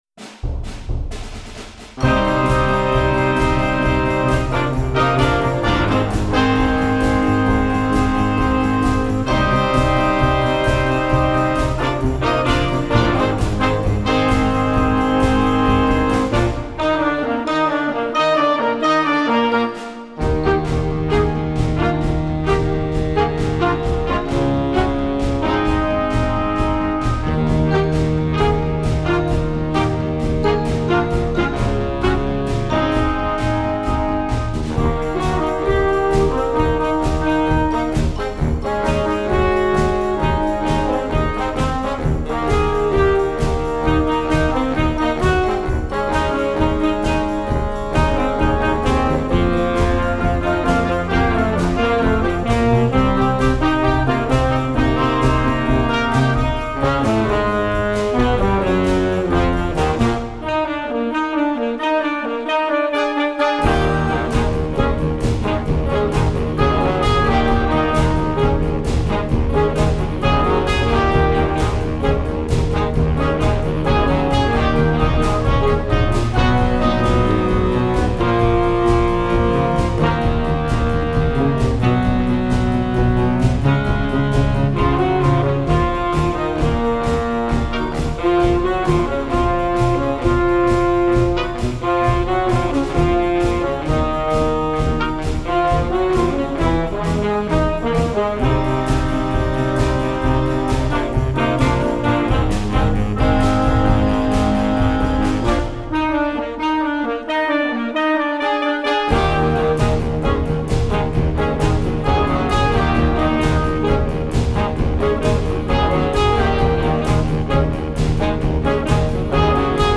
Gattung: für Jugendblasorchester
Besetzung: Blasorchester
klassische Rock-Medley